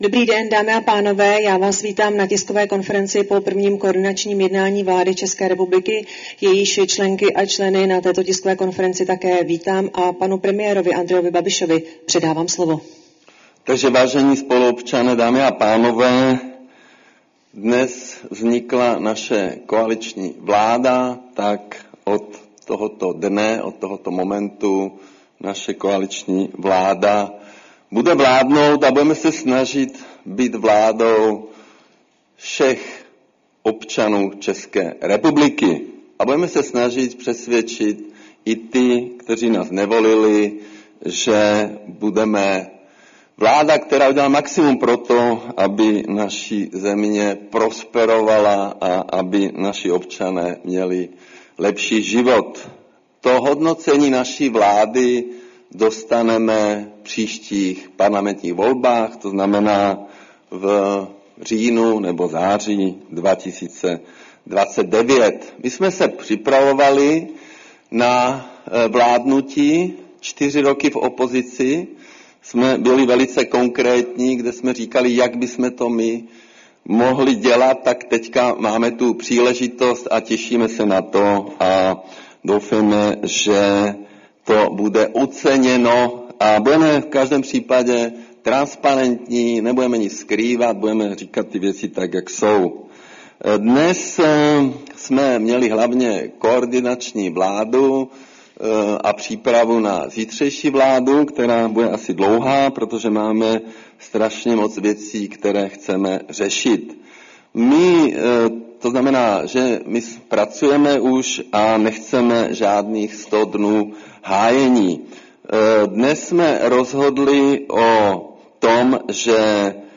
Tisková konference po ustavujícím zasedání vlády Andreje Babiše, 15. prosince 2025